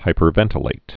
(hīpər-vĕntl-āt)